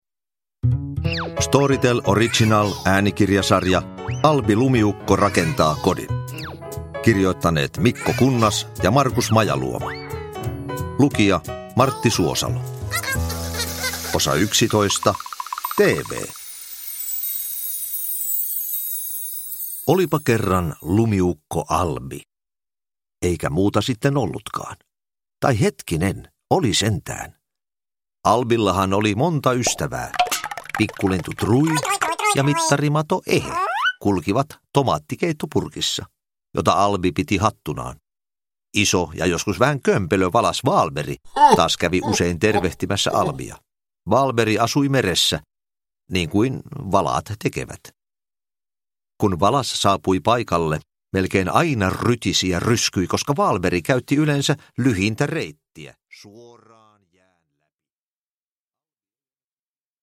Albi rakentaa kodin: TV – Ljudbok – Laddas ner
Uppläsare: Martti Suosalo